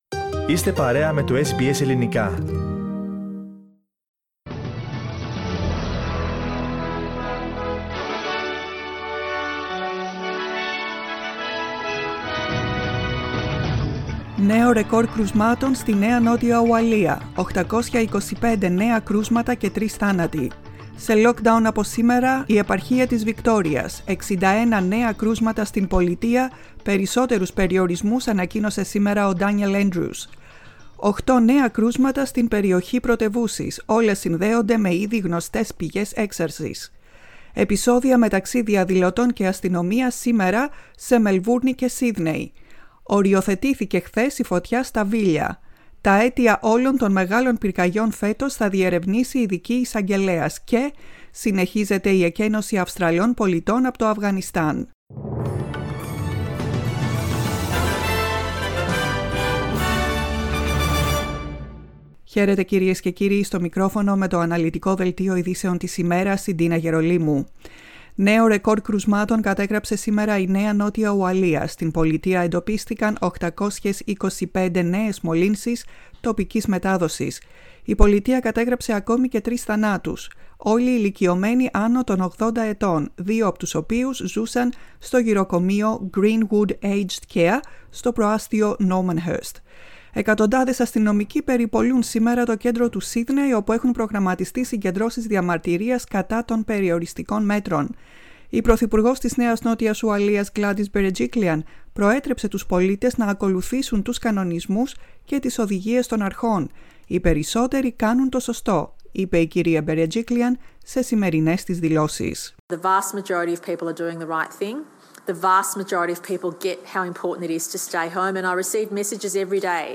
The main bulletin from the Greek Program.